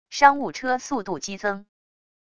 商务车速度激增wav音频